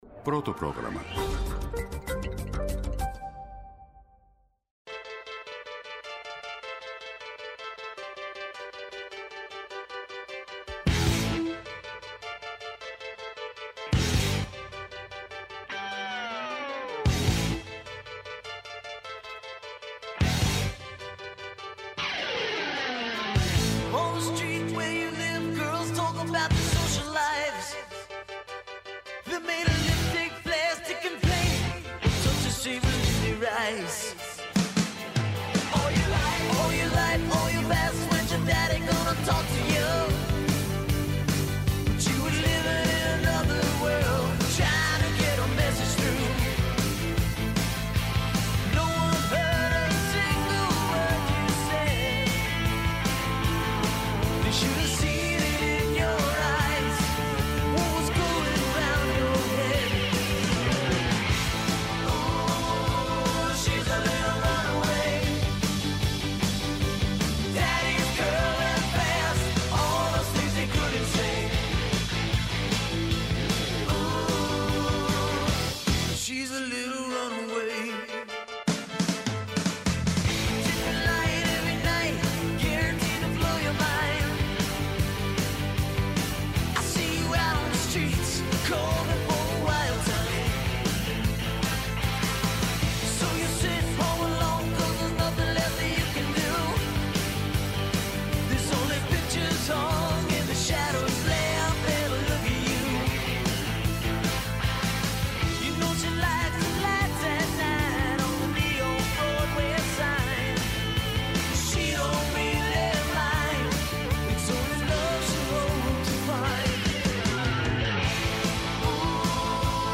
-Ο Θανάσης Θεοχαρόπουλος, μέλος της Πολιτικής Γραμματείας του ΣΥΡΙΖΑ Π.Σ.